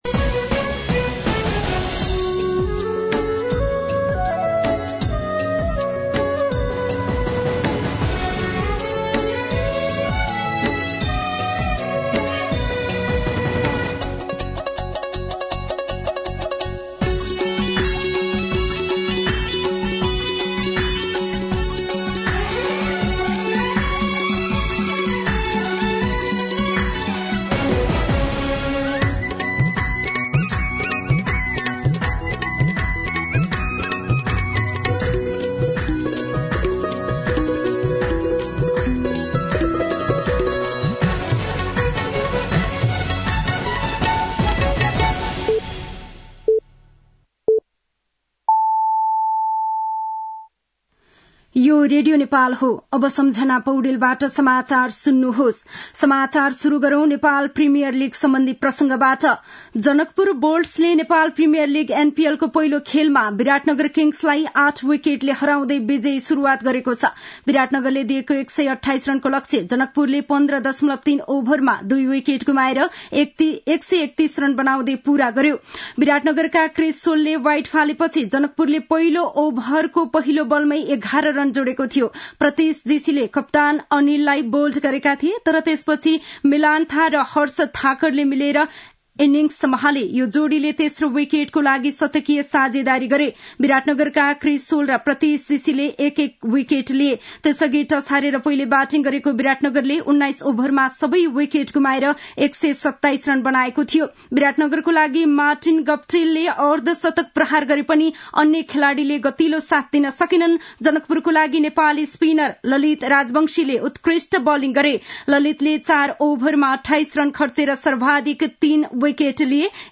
दिउँसो ४ बजेको नेपाली समाचार : १६ मंसिर , २०८१
4-pm-nepali-news-1-8.mp3